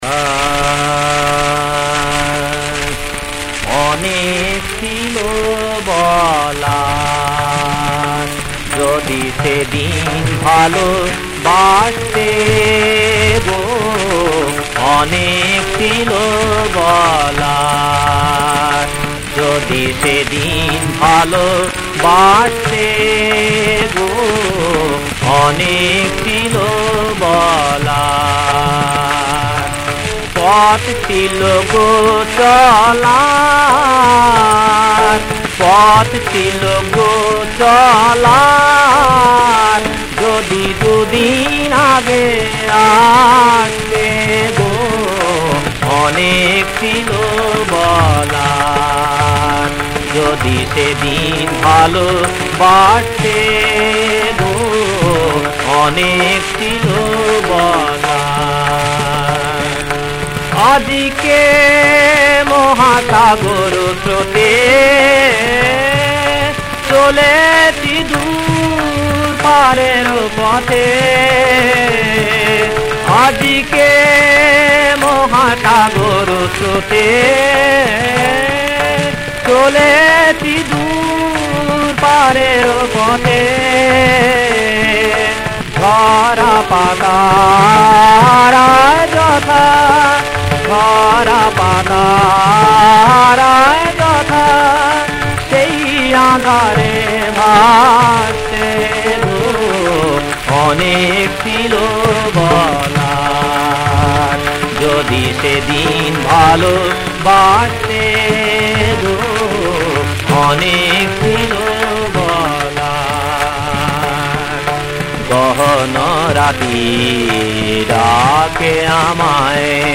• তাল: কাহারবা
• গ্রহস্বর: সা